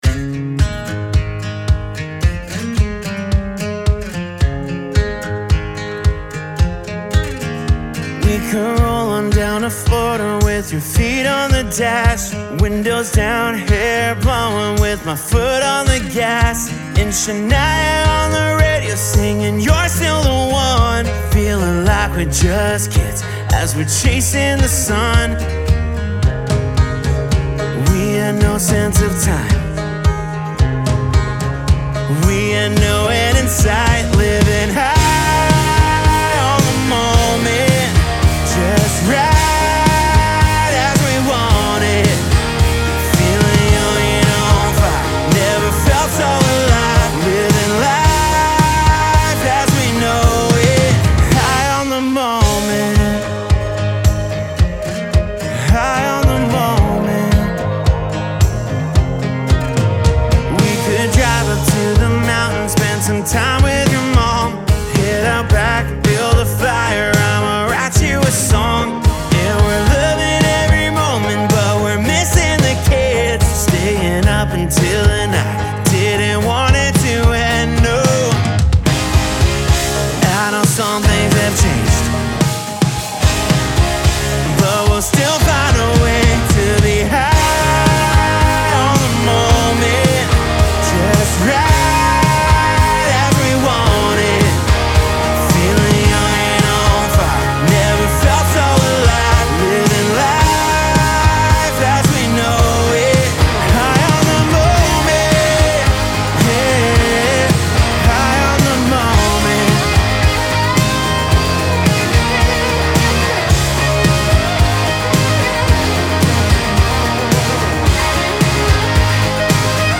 country rock outfit
rock ‘n’ roll-driven country music
guitars
drums